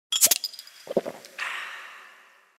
fallout drink Meme Sound Effect
fallout drink.mp3